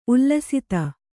♪ ullasita